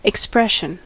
expression: Wikimedia Commons US English Pronunciations
En-us-expression.WAV